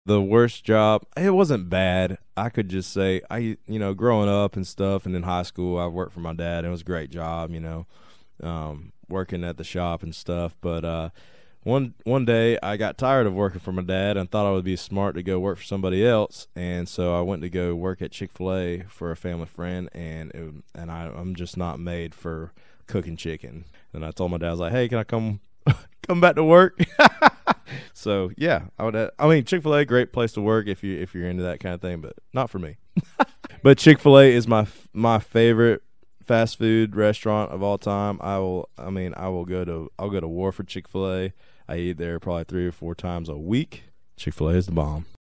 Audio / Jon Langston talks about working